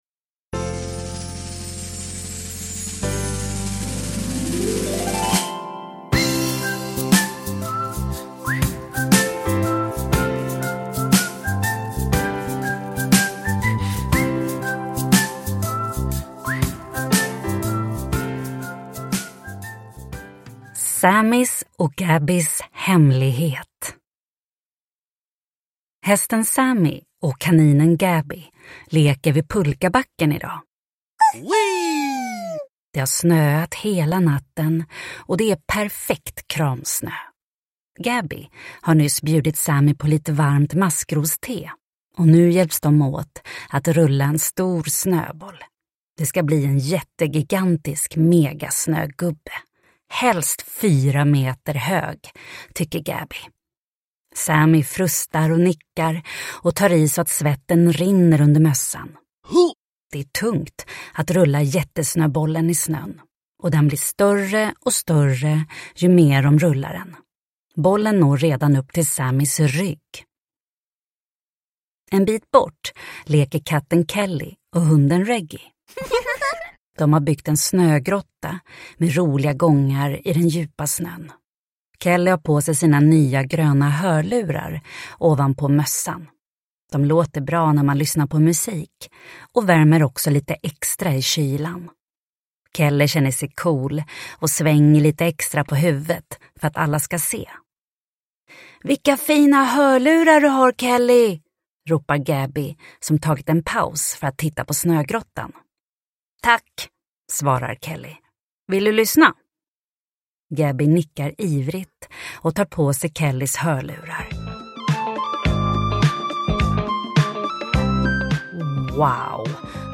Sammys och Gabbys hemlighet – Ljudbok – Laddas ner